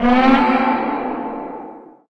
bankAlarm.ogg